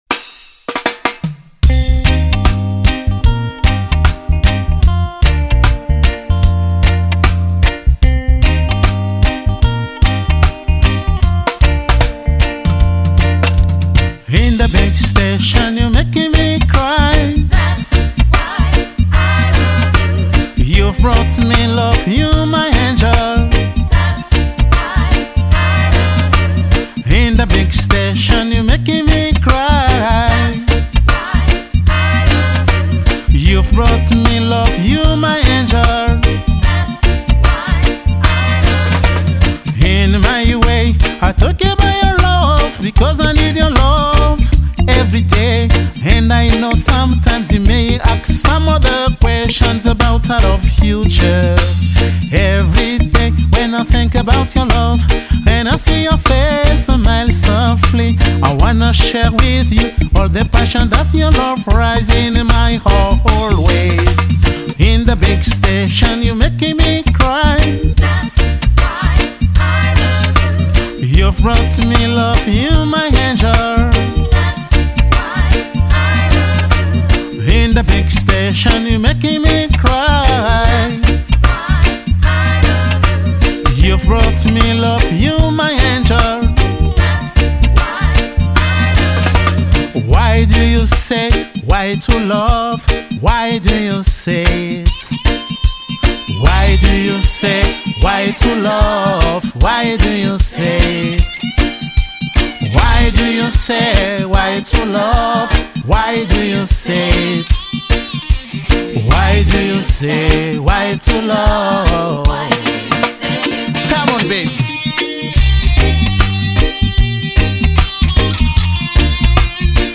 a été enregistré dans les conditions d’un live.